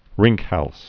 (rĭngkhăls)